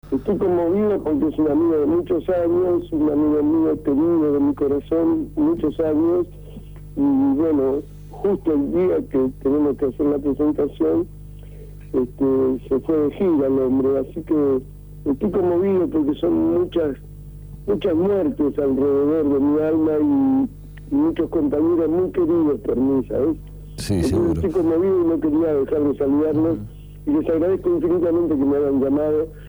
por Radio Grafica FM 89.3
CONMOVIDO